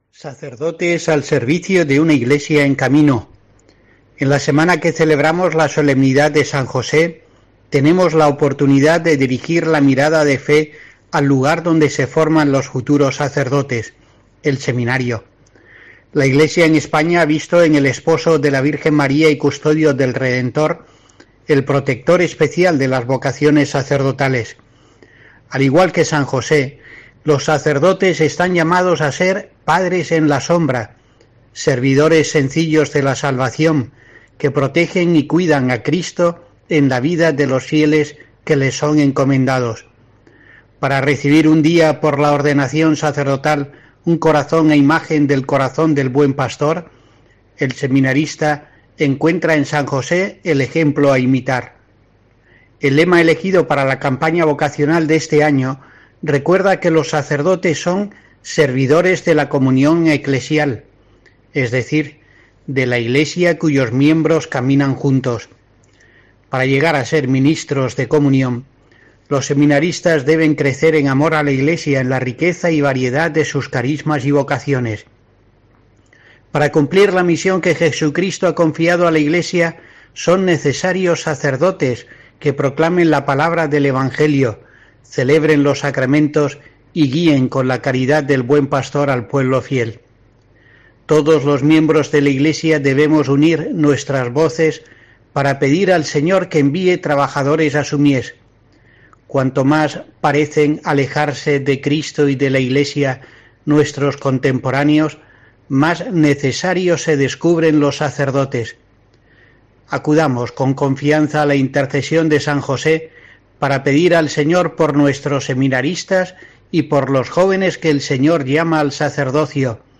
El obispo de Asidonia-Jerez insta el ejemplo de San José en su comentario de vísperas del Día del Seminario que llega con el lema 'Sacerdotes al servicio de una Iglesia en camino'